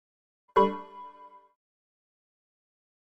Play Microsoft Windows 98 Error - SoundBoardGuy
Play, download and share Microsoft Windows 98 Error original sound button!!!!
microsoft-windows-98-error.mp3